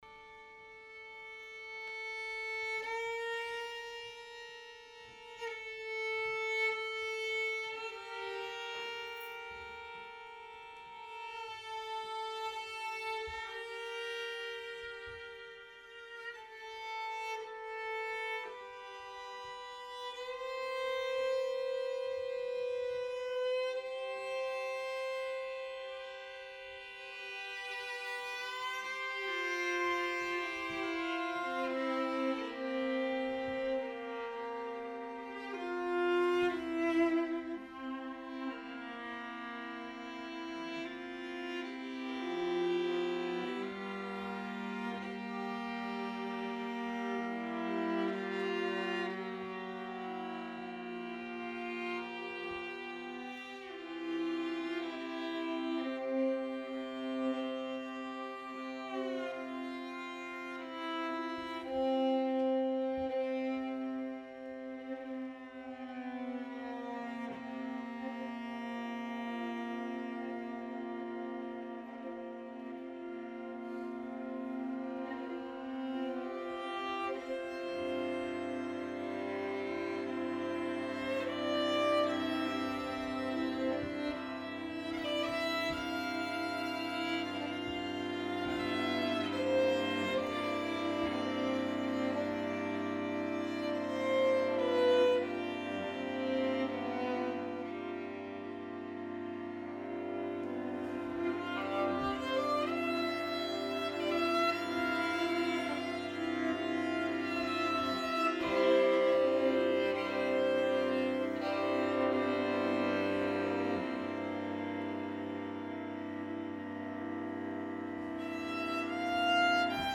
Venue: Bantry Library
Instrumentation Category:String Quartet